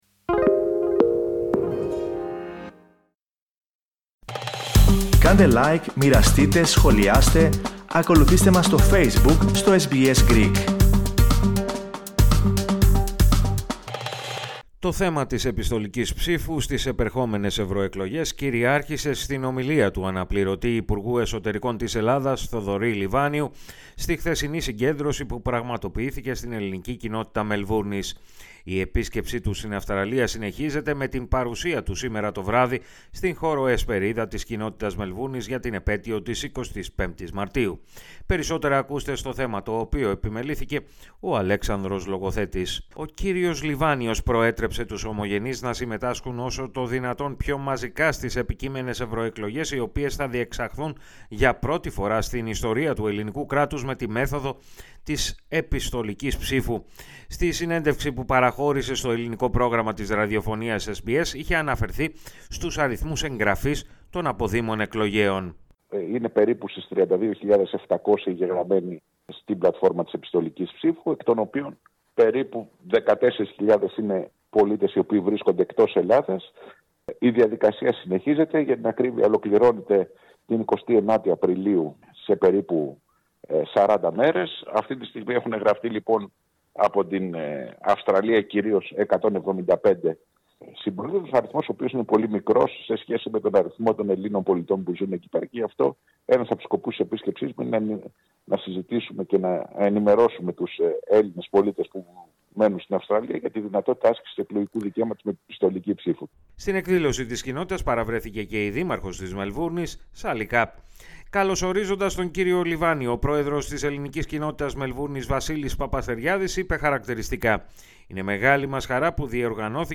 Το θέμα της επιστολικής ψήφου στις επερχόμενες Ευρωεκλογές, κυριάρχησε στην ομιλία του αναπληρωτή υπουργού Εσωτερικών της Ελλάδας, Θοδωρή Λιβάνιου, στη χθεσινή συγκέντρωση που πραγματοποιήθηκε στην Ελληνική Κοινότητα Μελβούρνης.